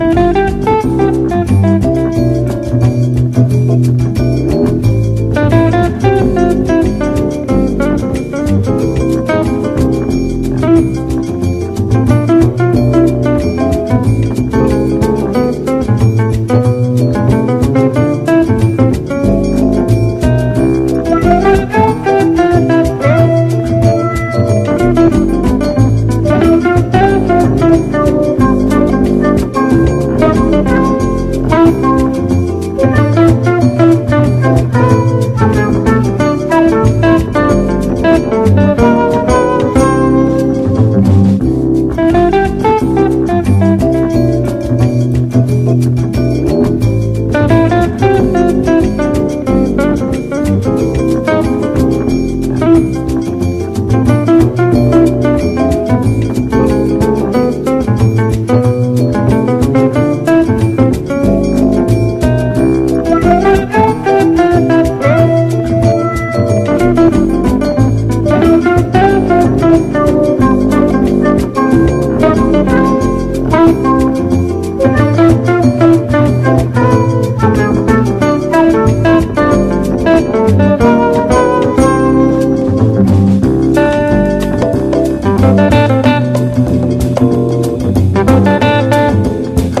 JAZZ / MAIN STREAM / JAZZ VOCAL